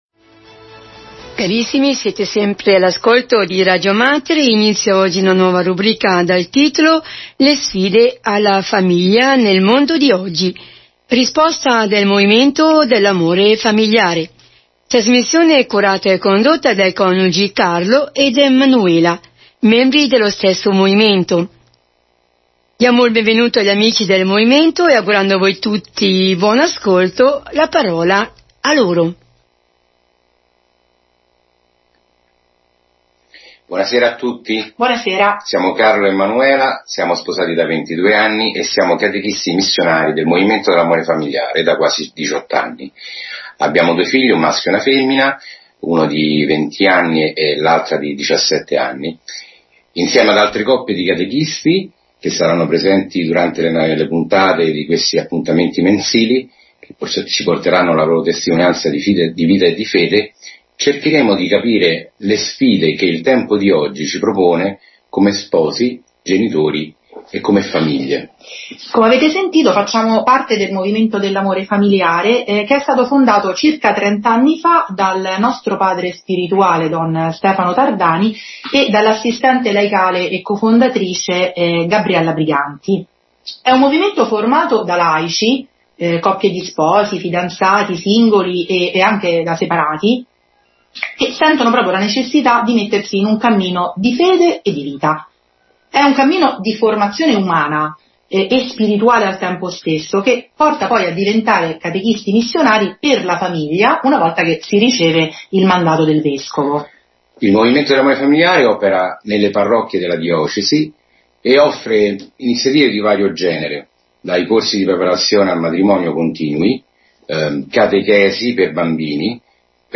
Potete riascoltare qui le puntate trasmesse su Radio Mater: